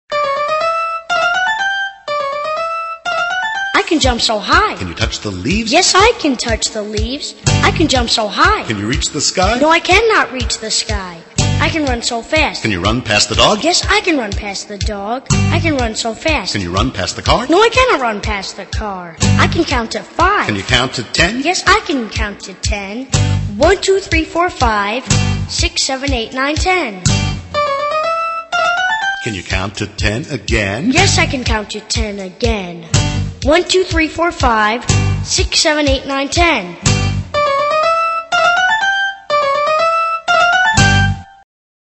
在线英语听力室英语儿歌274首 第79期:I can jump so high的听力文件下载,收录了274首发音地道纯正，音乐节奏活泼动人的英文儿歌，从小培养对英语的爱好，为以后萌娃学习更多的英语知识，打下坚实的基础。